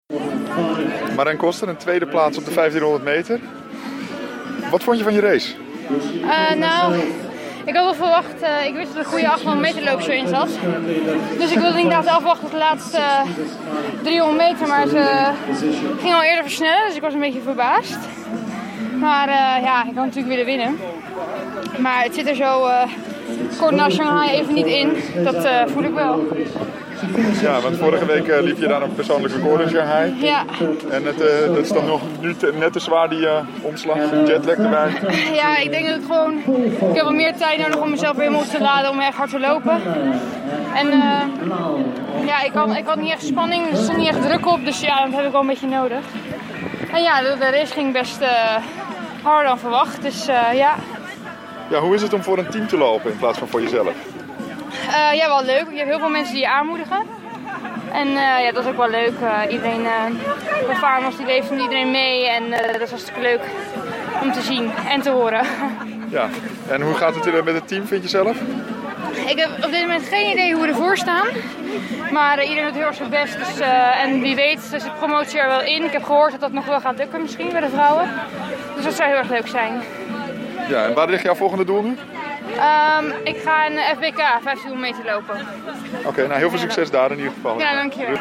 Zaterdag vond in het Olympisch Stadion de European Champions Clubs Cup plaats.